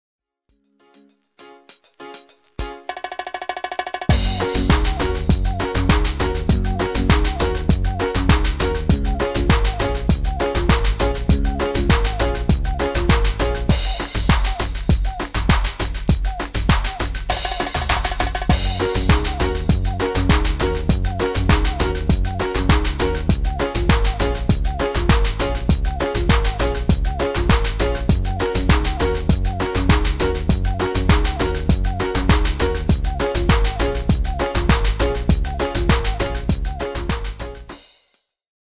Demo/Koop midifile
Genre: Reggae / Latin / Salsa
- Vocal harmony tracks